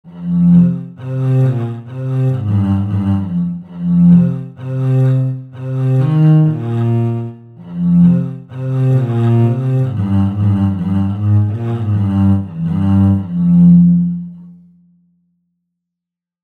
Viola da gamba
Dźwięk wydobywa się pocierając smyczkiem struny.
Viole da gamba mają ciemne i niskie brzmienie.
Dźwięki instrumentów są brzmieniem orientacyjnym, wygenerowanym w programach:
Viola-da-gamba.mp3